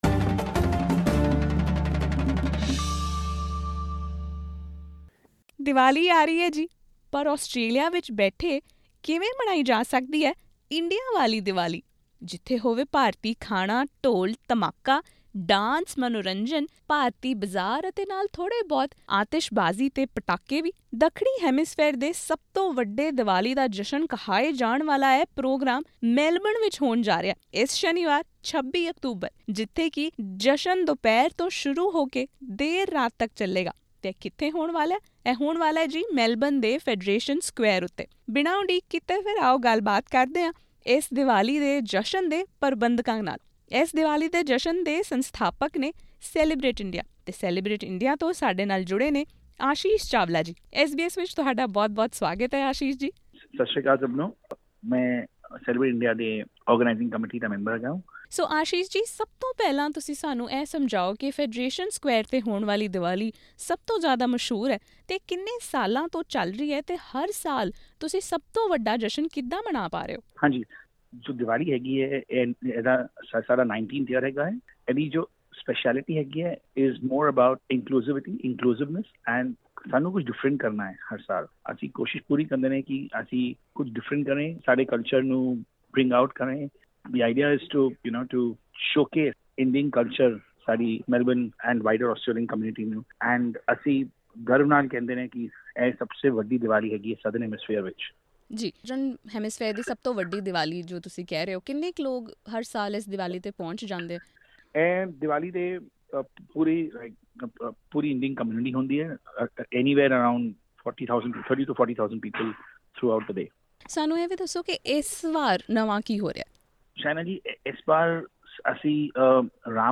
Melbourne is hosting what is touted as the biggest diwali celebration in the southern hemisphere at Federation square. SBS Punjabi talked to organisers to figure out what's new this yer.